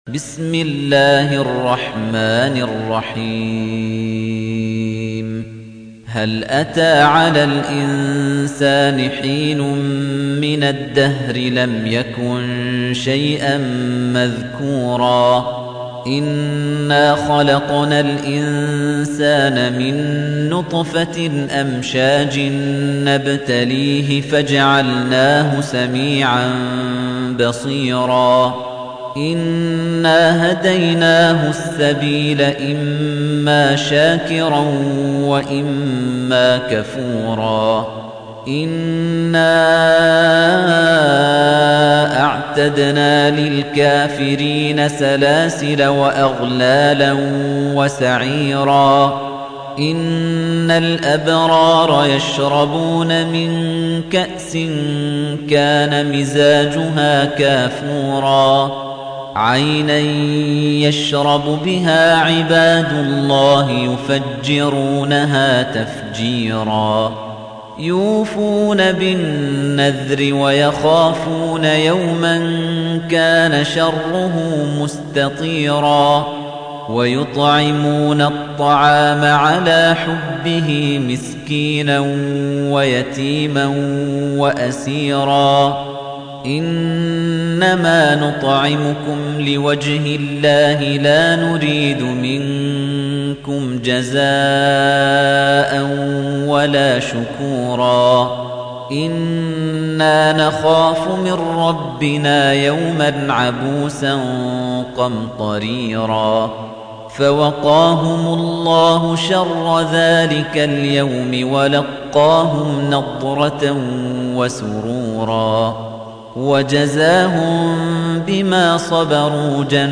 تحميل : 76. سورة الإنسان / القارئ خليفة الطنيجي / القرآن الكريم / موقع يا حسين